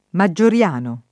vai all'elenco alfabetico delle voci ingrandisci il carattere 100% rimpicciolisci il carattere stampa invia tramite posta elettronica codividi su Facebook Maggioriano [ ma JJ or L# no ] (raro Maioriano ) pers. m. stor.